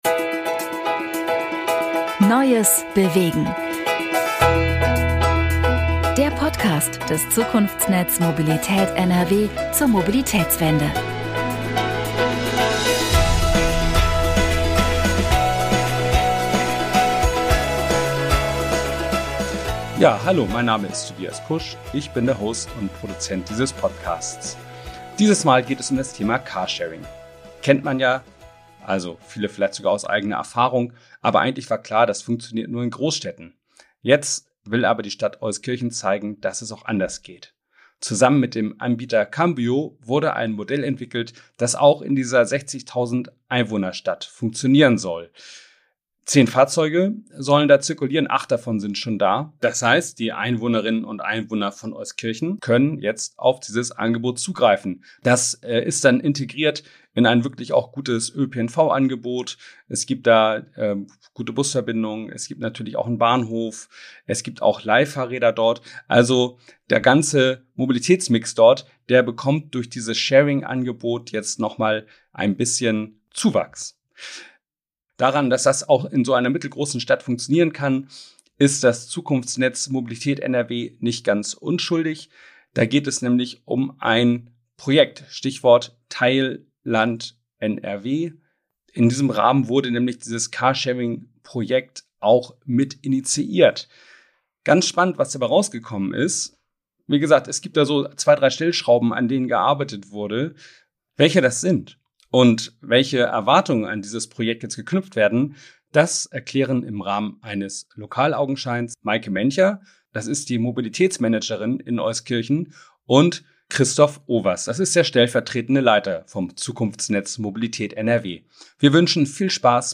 Bei einer Rundfahrt mit dem Carsharing-Auto